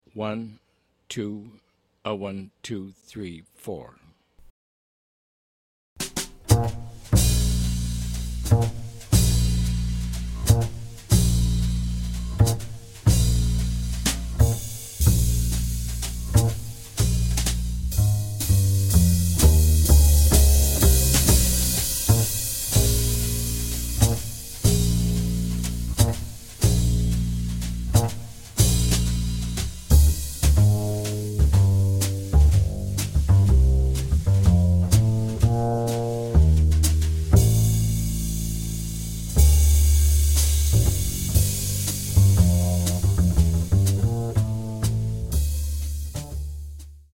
melodic, standard-like but surprisingly intricate song
trio recording, with Minus You audio tracks